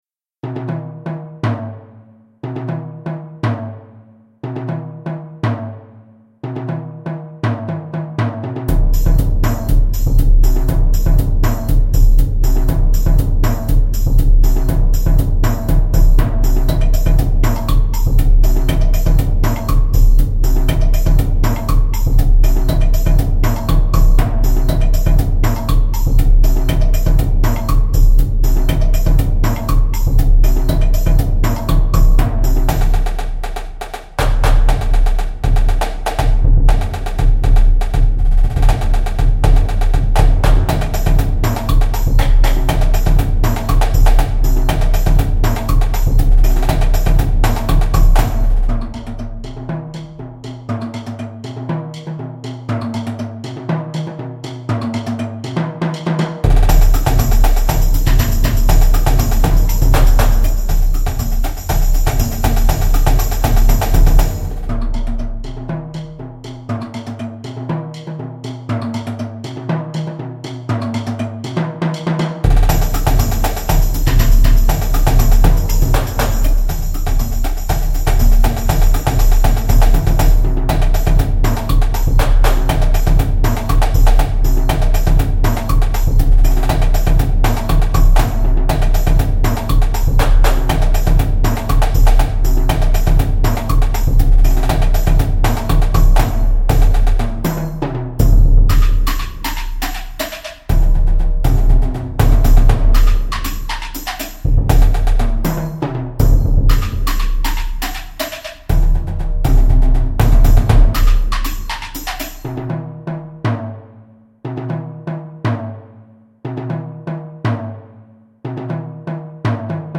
Jazz & Latin